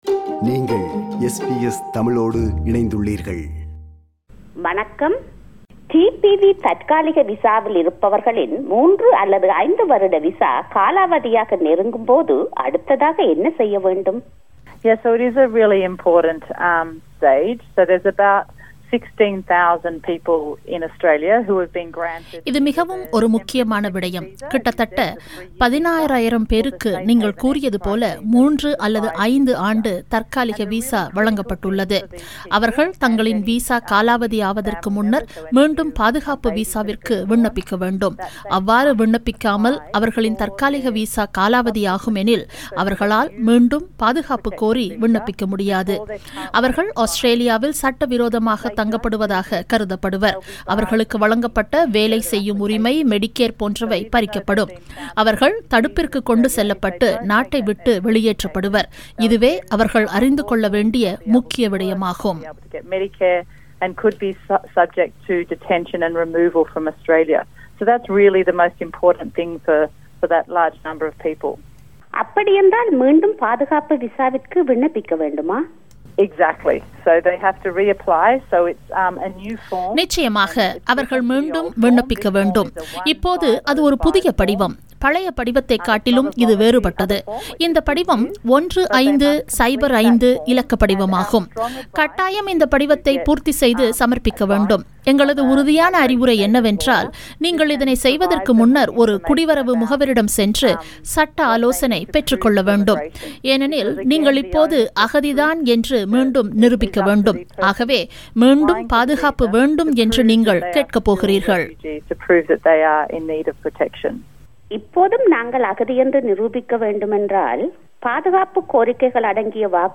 அகதி ஒருவரின் கேள்விகளுக்கு பதில் அளிக்கிறார்